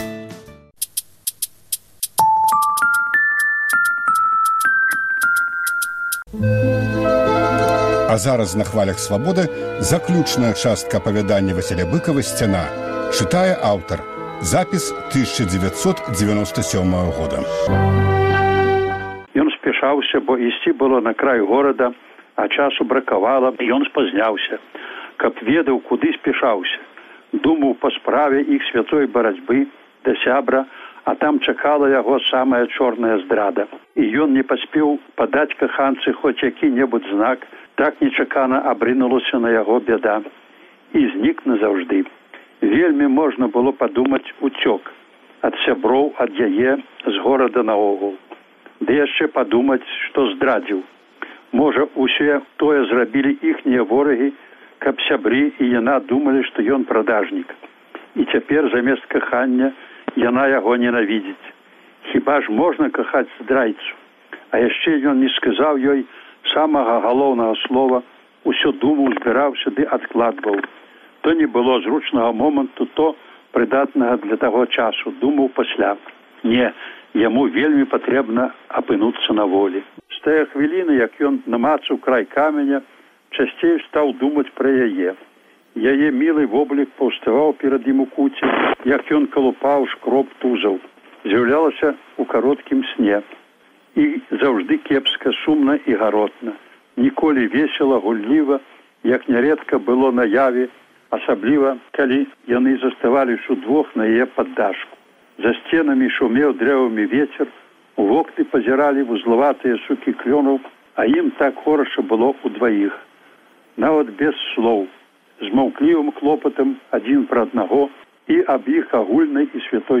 Апавяданьне "Сьцяна" чытае Васіль Быкаў.